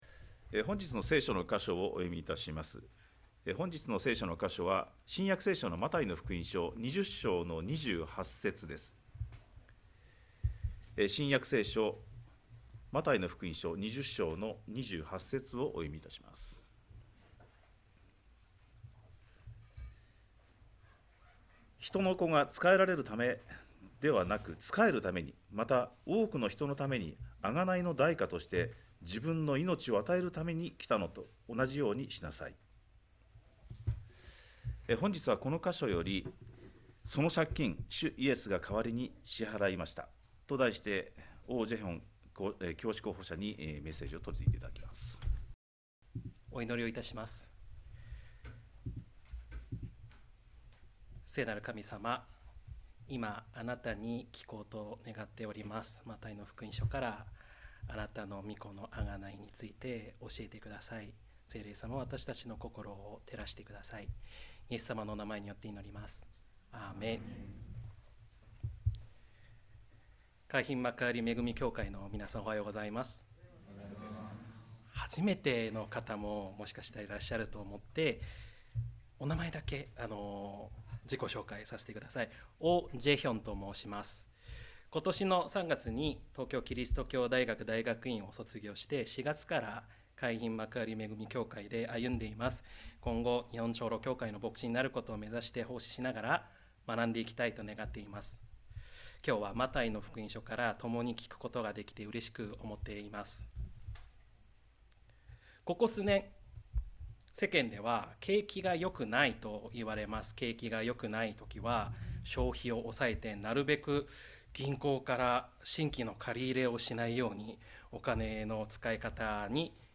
2025年4月13日礼拝 説教 「その借金、主イエスが代わりに支払いました」 – 海浜幕張めぐみ教会 – Kaihin Makuhari Grace Church
礼拝式順